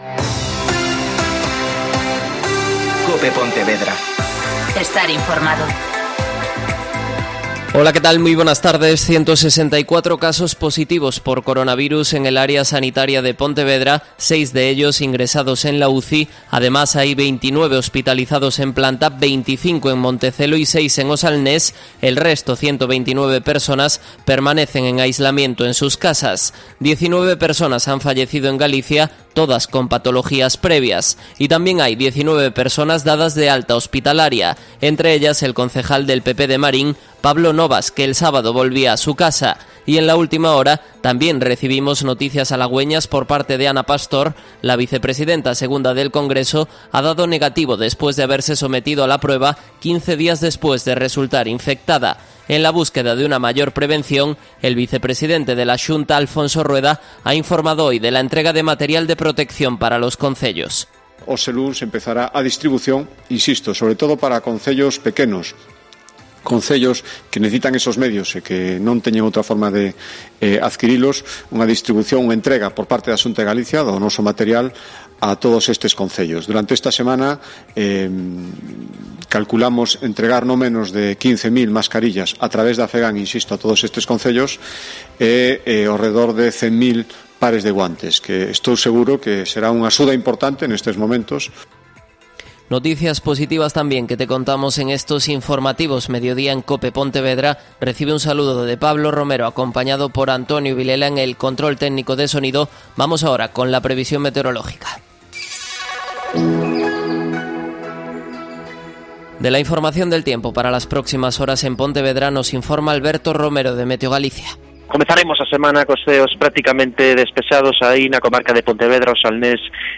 Mediodía COPE Pontevedra (Informativo 14.20h)